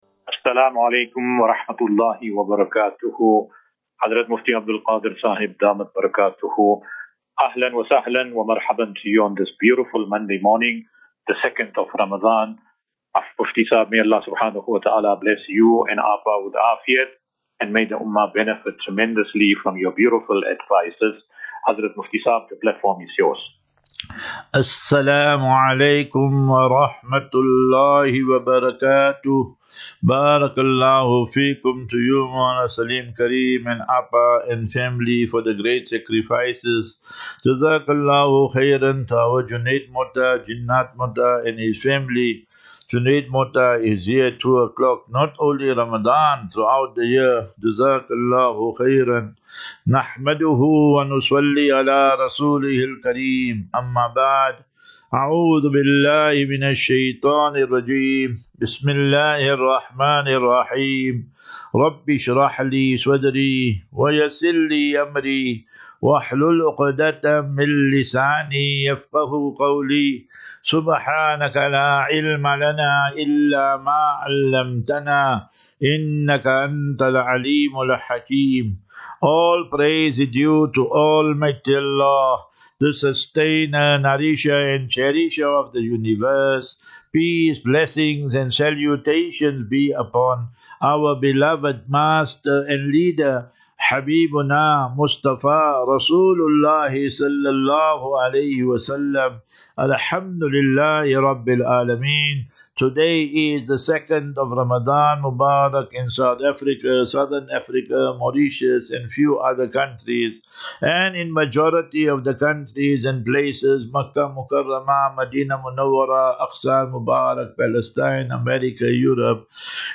As Safinatu Ilal Jannah Naseeha and Q and A 3 Mar 03 March 2025.